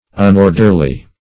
Unorderly \Un*or"der*ly\